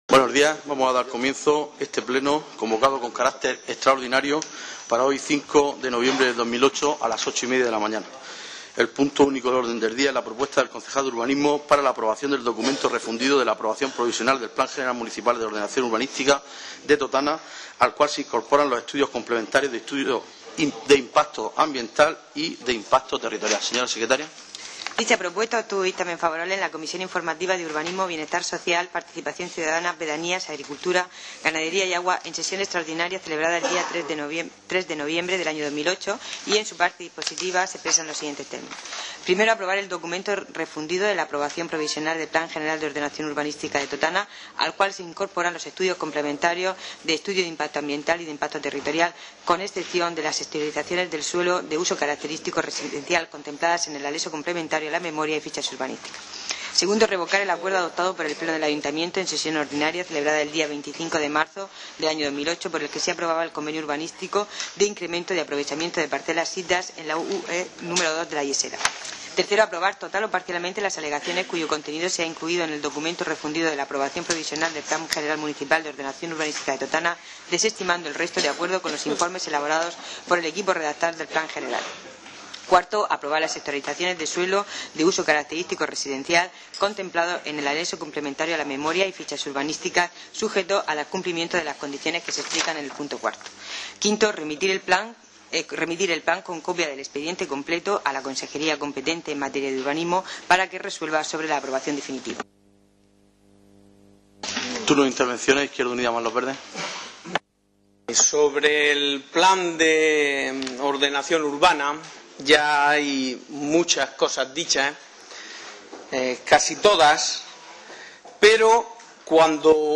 El Pleno del Ayuntamiento de Totana ha aprobado, en sesión extraordinaria, con los votos a favor del Partido Popular, la abstención del PSOE y en contra los de Izquierda Unida+Los Verdes, remitir el documento íntegro del Plan General Municipal de Ordenación Urbanística (PGMOU) a la Consejería competente para que resuelva su aprobación definitiva, según informaron fuentes municipales.